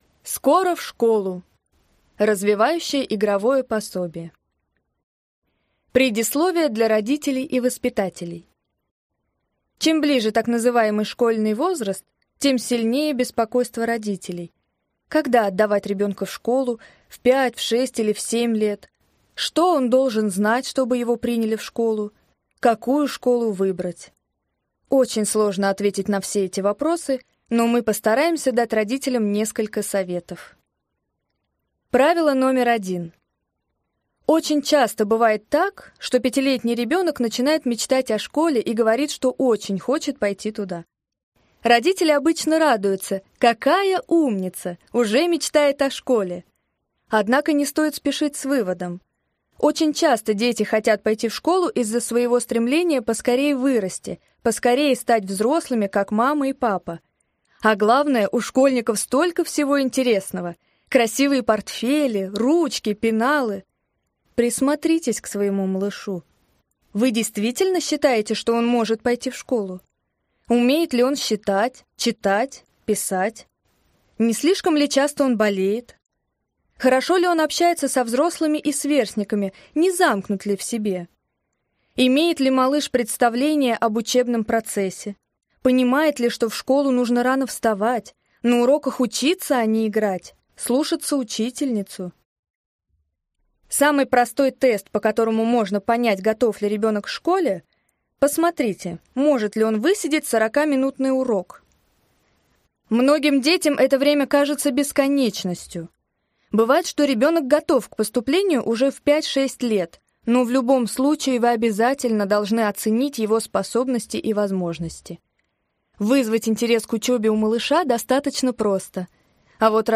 Аудиокнига Готовимся к школе 5-7 лет | Библиотека аудиокниг